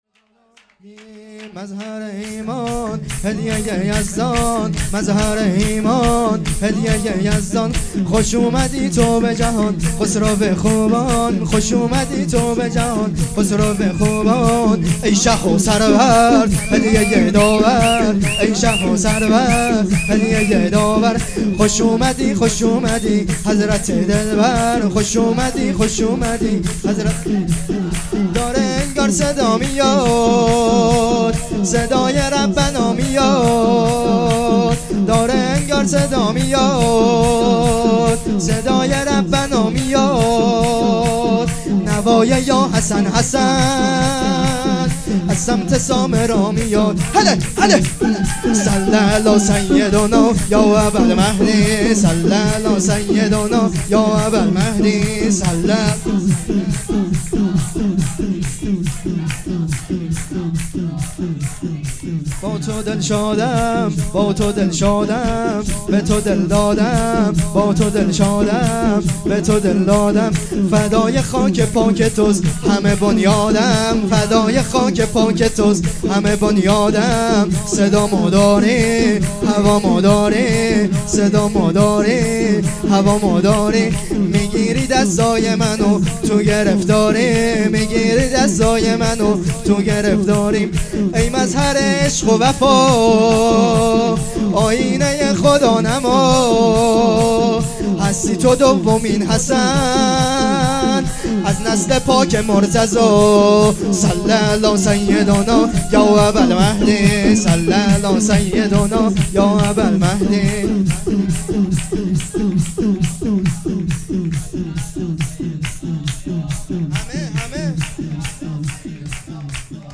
جشن ولادت امام حسن عسکری (ع) ۱۴۰۱